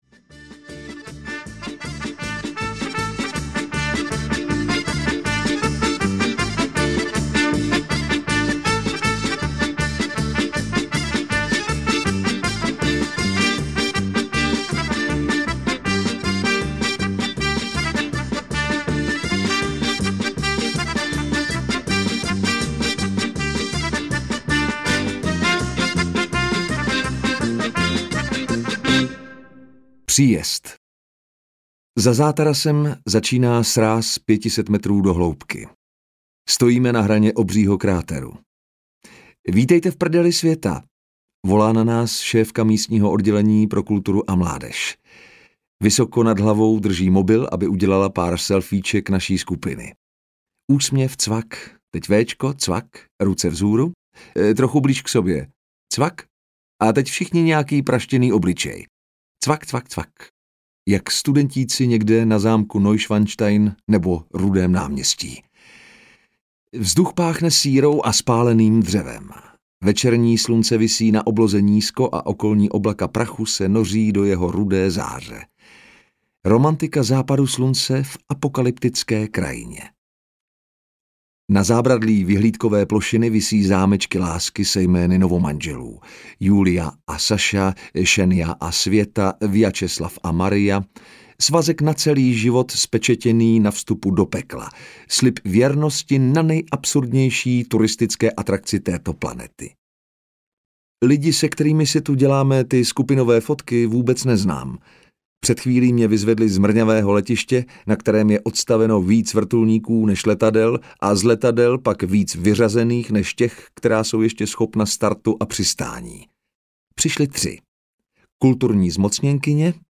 Couchsurfing v Rusku audiokniha
Ukázka z knihy